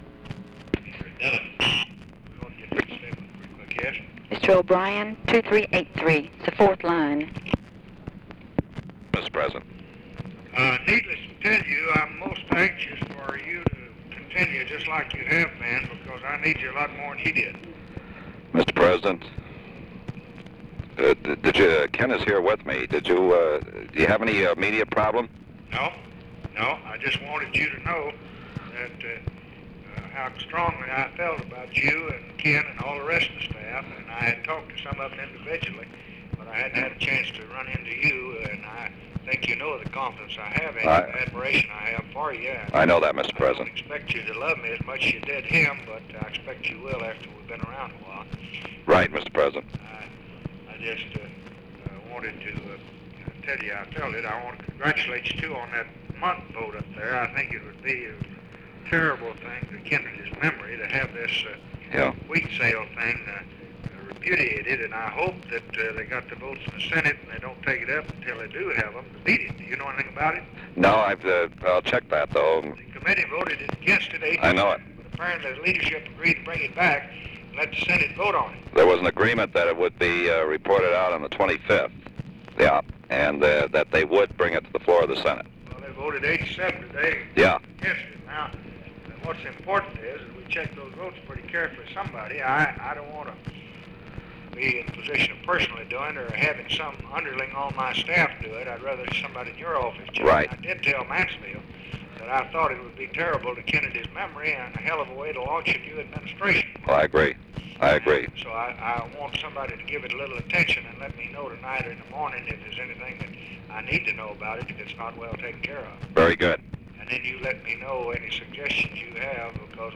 Conversation with LARRY O'BRIEN, November 25, 1963
Secret White House Tapes